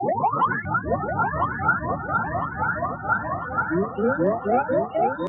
描述：混合的色调
Tag: 电子 序列 音调